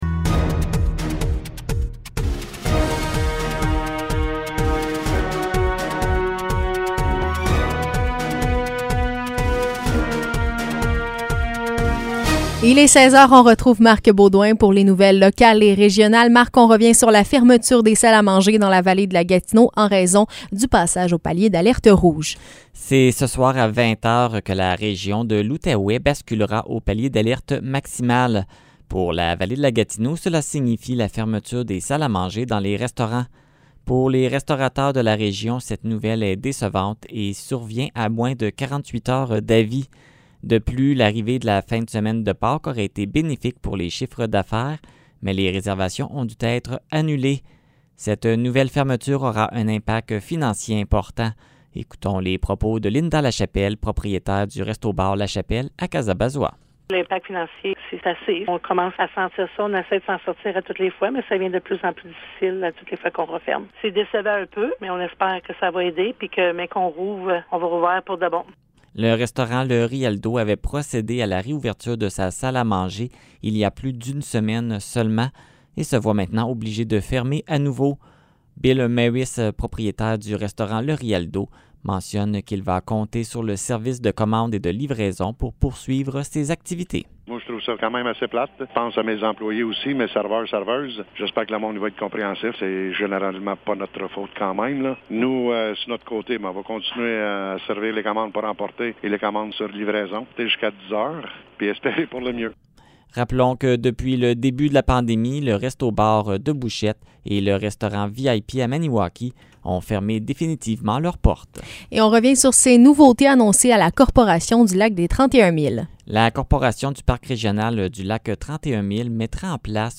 Nouvelles locales - 1er Avril 2021 - 16 h